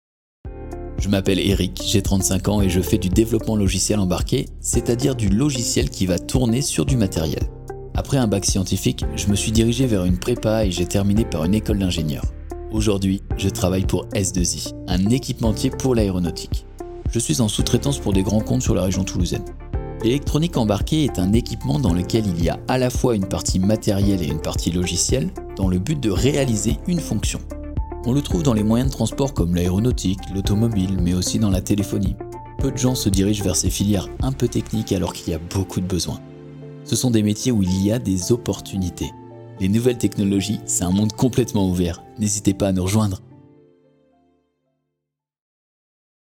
25 - 50 ans - Baryton-basse